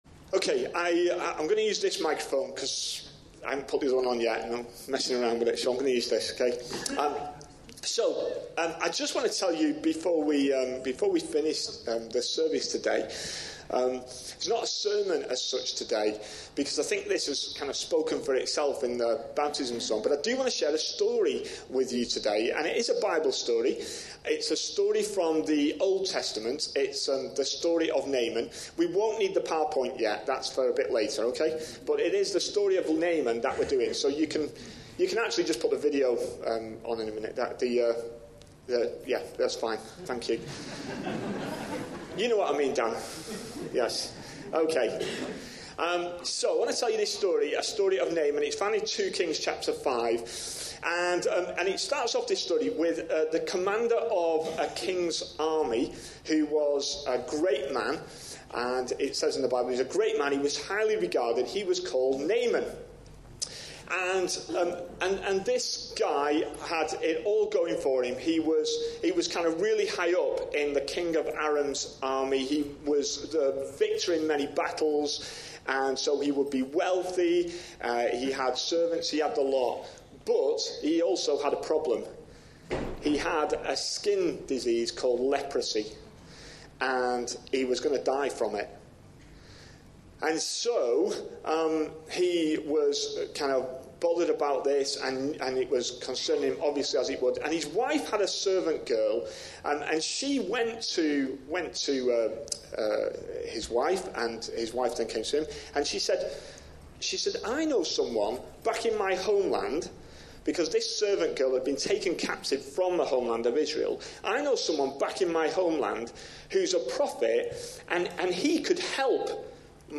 A sermon preached on 13th January, 2019.
A sermon preached on 13th January, 2019. 2 Kings 5:1-15a Listen online Details A short talk to conclude a baptismal service; no reading, but based on the story of Naaman being cured by washing in the River Jordan in 2 Kings 5:1-15a, with references to John 3:16, Matt 7:7/Lk 11:9, and Acts 2:38.